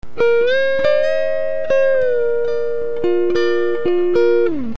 Original Unprocessed Signal